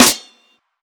SDFGGH2_SNR.wav